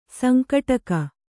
♪ sankaṭaka